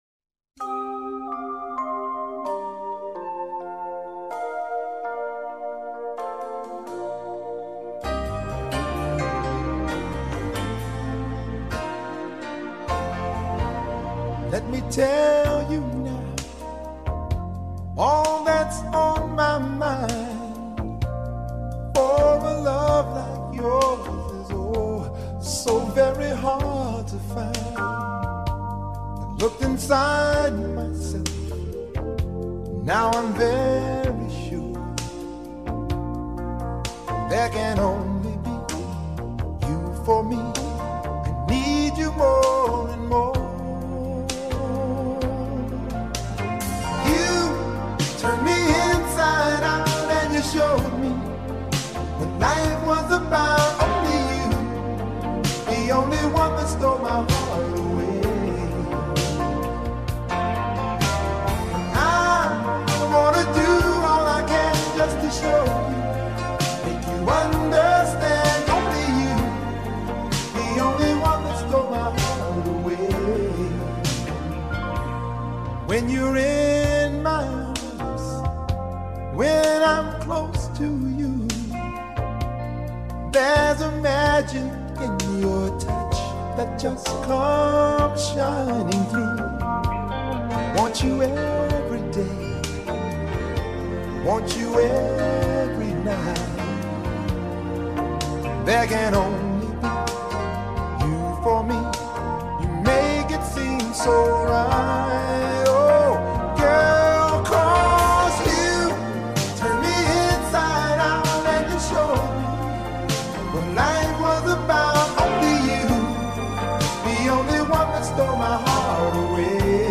lagu balada cinta